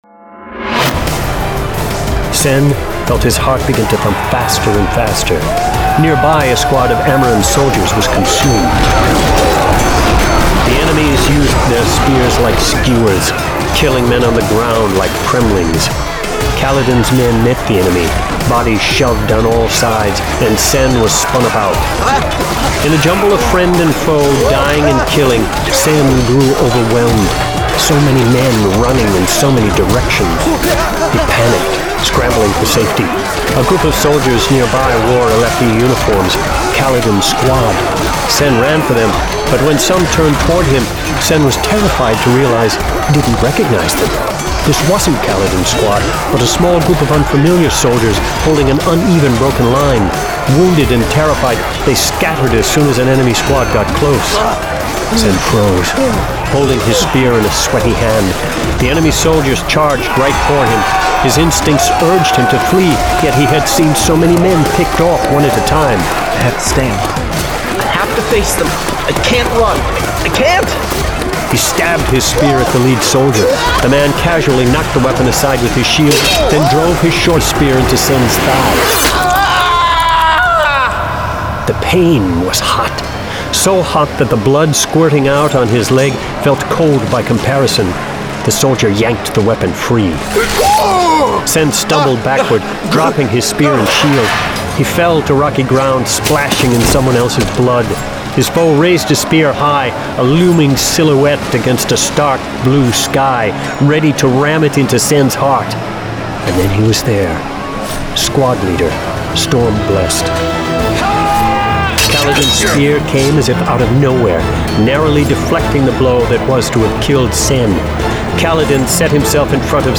Full Cast. Cinematic Music. Sound Effects.